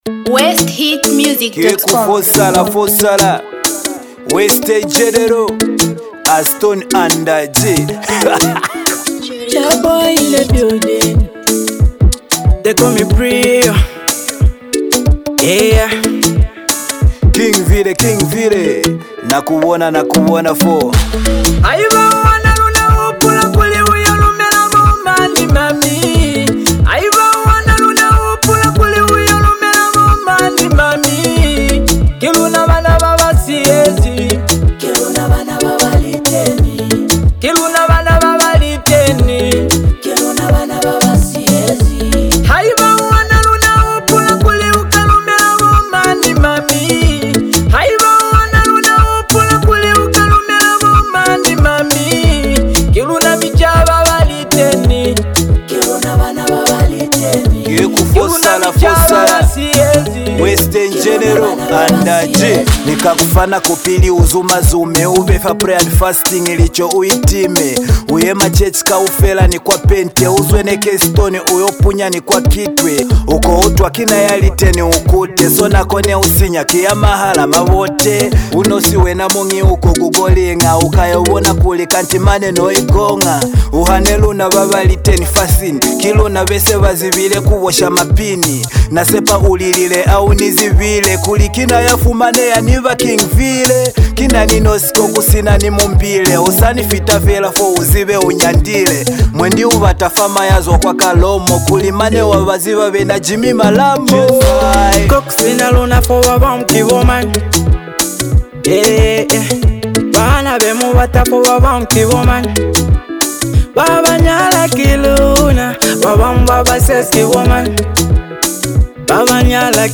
a perfect blend of rhythmic beats and captivating lyrics
hip-hop and rap